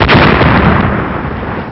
iconic_explosion1.wav